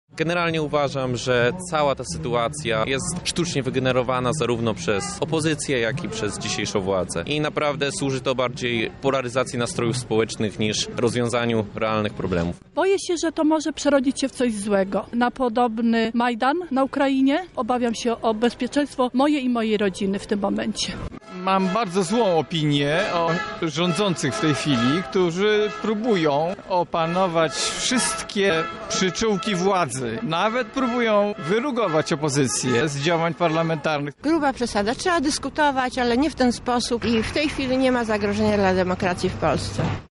W Lublinie ok. 300 osób wzięło udział w pikiecie odbywającej się przed ratuszem. Wśród protestujących byli głównie zwolennicy Nowoczesnej oraz KOD-u. Na miejscu był nasz reporter
sonda